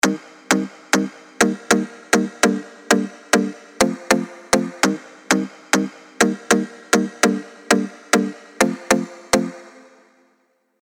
Нужен такой PLUCK
Острый с призвуком калимбы.
Вложения Pluck.mp3 Pluck.mp3 423,1 KB · Просмотры: 366